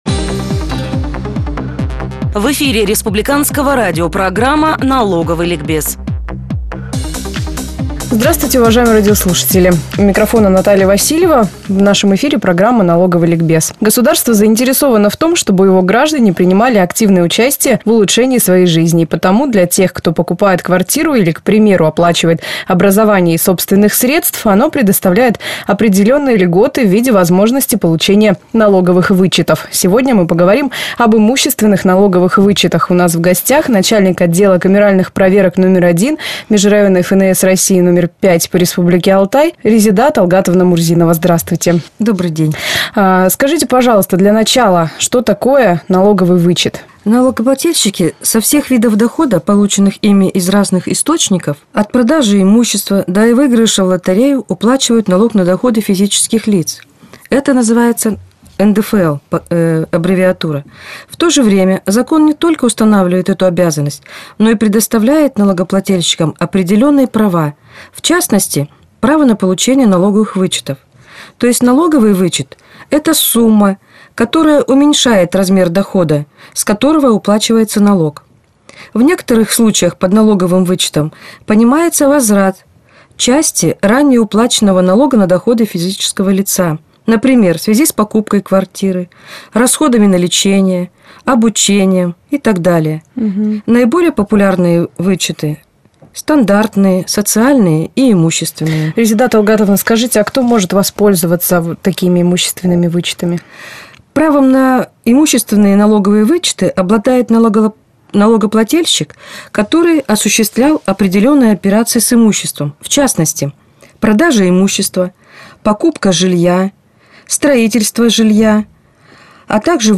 в эфире «Радио России»